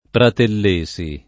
[ pratell %S i ]